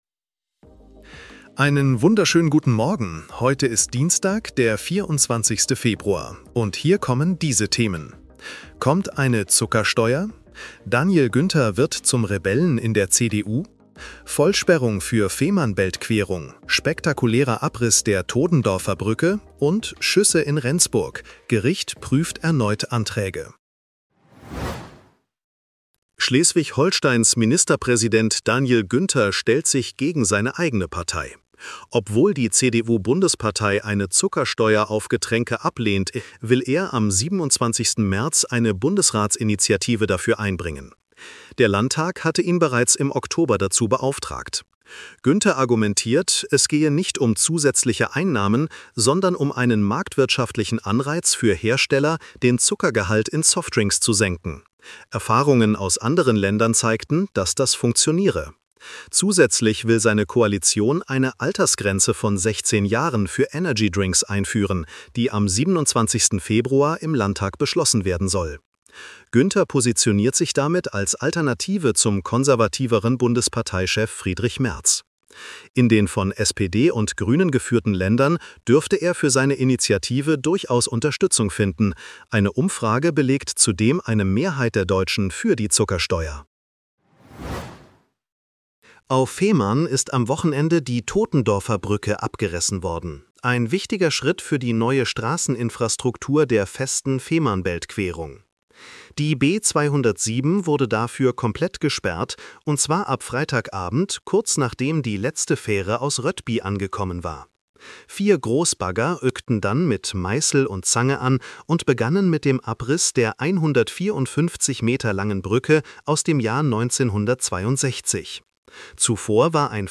Nachrichten-Botcast bekommst Du ab 7:30 Uhr die wichtigsten Infos